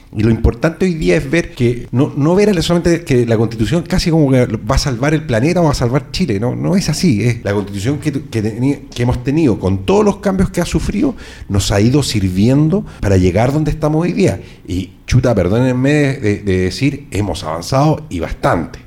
En el segundo capítulo del programa de Radio Sago “Dialogo Constitucional” se continuó analizando la situación actual que vive el país en torno al proceso constitucional. En esta edición estuvieron como invitados los concejales de Puerto Montt Héctor Ulloa (PPD) y Fernando Orellana (RN), debatiendo sobre una posible modificación a la Constitución que rige en el país.
Los concejales fueron claros en manifestar que los municipios deben tener un rol participativo en los procesos de votación, sin interferir en la decisión de la comunidad. Cabe mencionar que el programa “Dialogo Constitucional” de nuestra emisora, se transmite todos los domingos, a las 11 y las 23 horas, contando con mesas de análisis y discusión de los distintos aspectos del proceso constituyente.